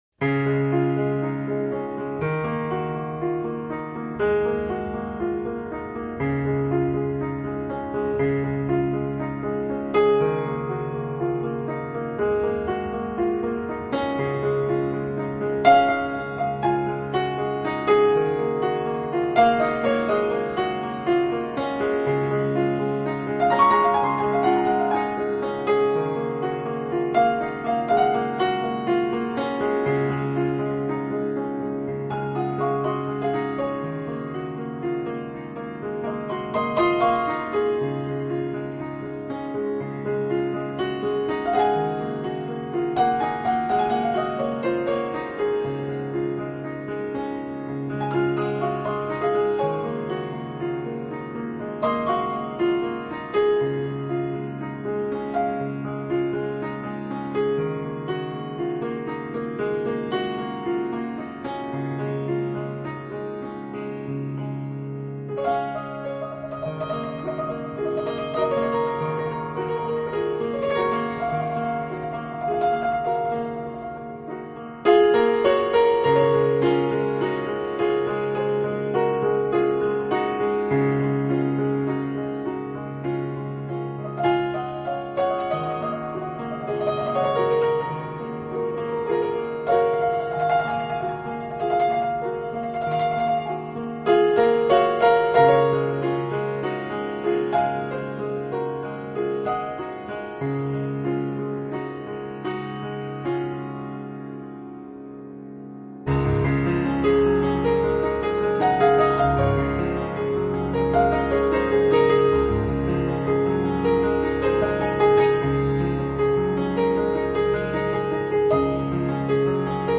【钢琴纯乐】
Style: New Age, Instrumental, Neo-classical, Piano Solo